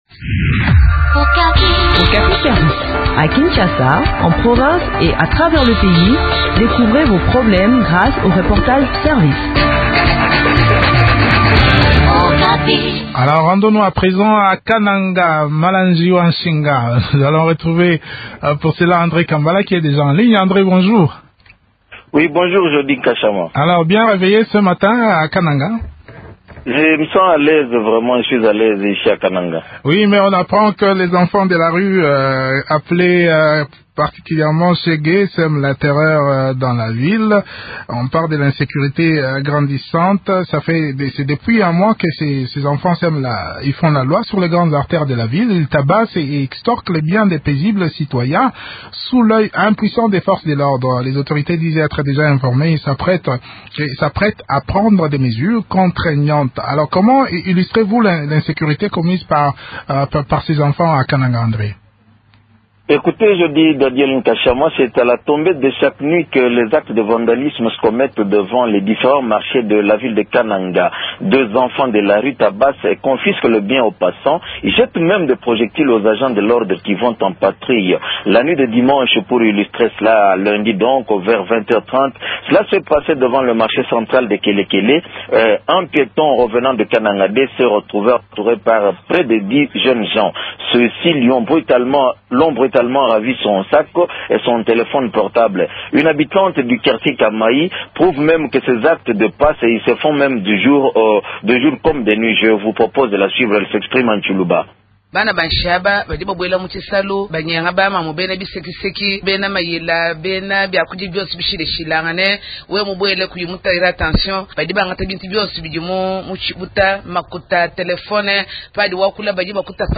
maire de la ville de Kananga.